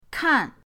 kan4.mp3